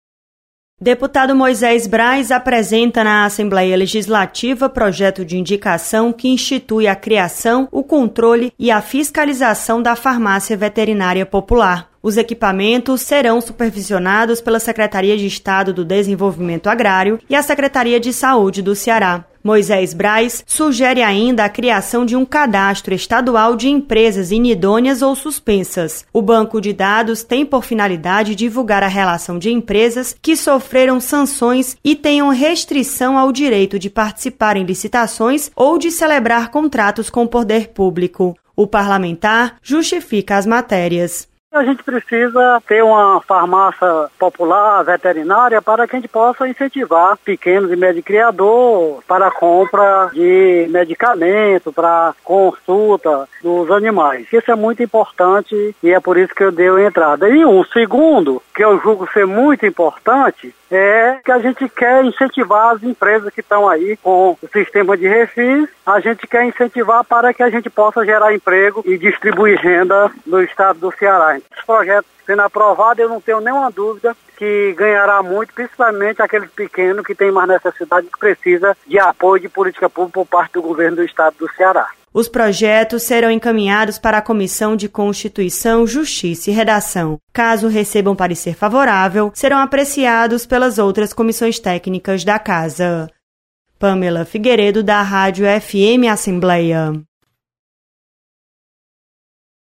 Projeto sugere criação de Farmácia Veterinária Popular. Repórter